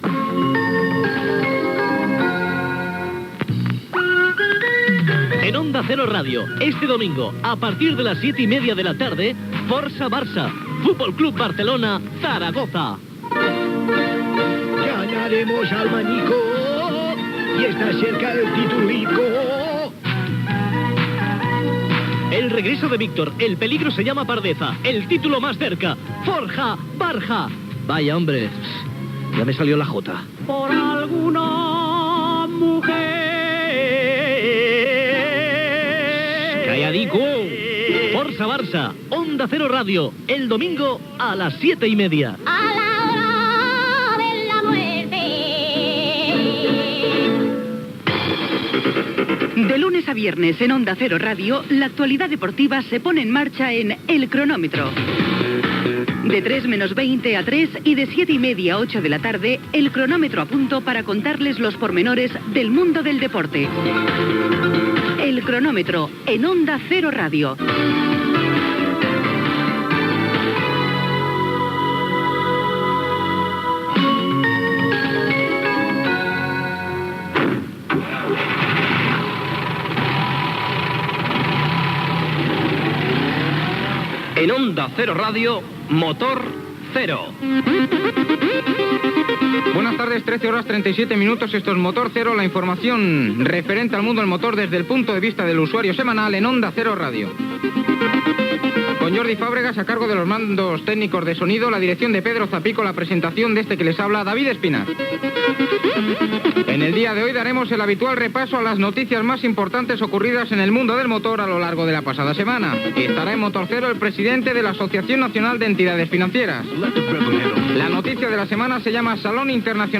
Promocions de la transmissió de "Força Barça" i del programa "El cronómetro". Inici del programa, equip, sumari, indicatiu, publicitat, indicatiu, actualitat de la setmana (inaugurat el Saló de l'Automòbil), hora